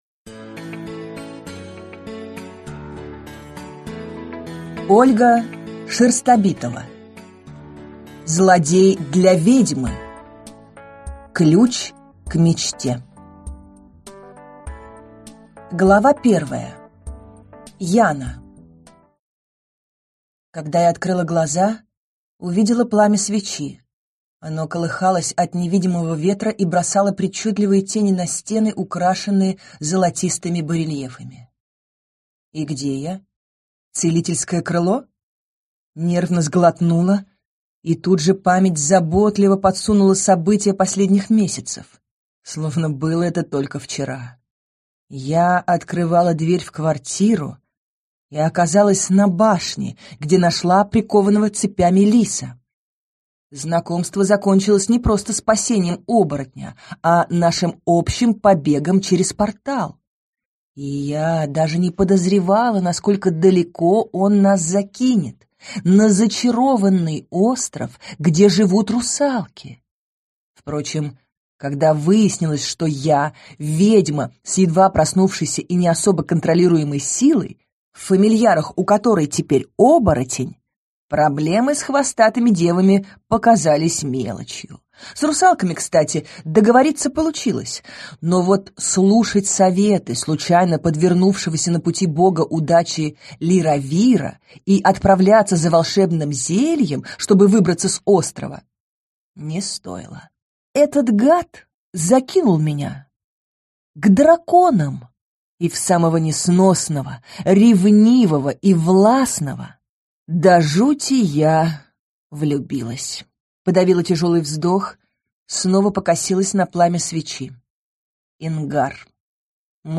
Аудиокнига Злодей для ведьмы. Ключ к мечте | Библиотека аудиокниг